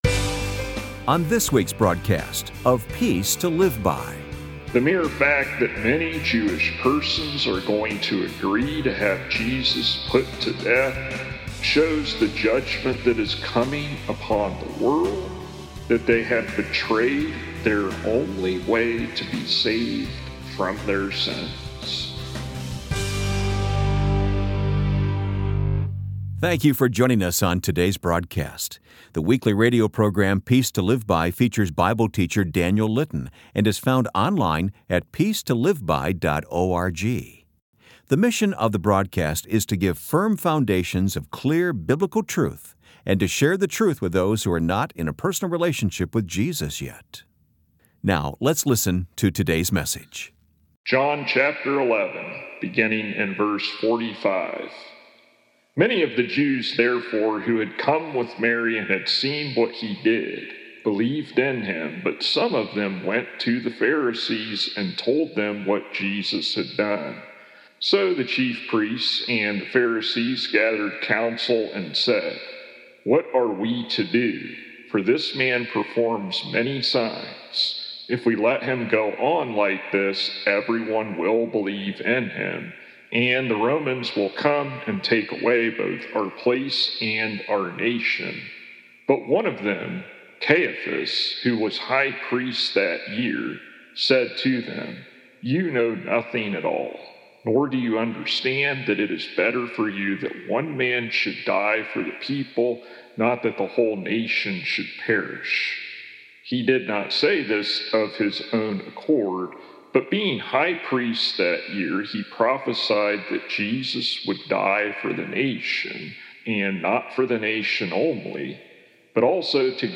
For full sermons without edits for time, tap here to go to downloads page.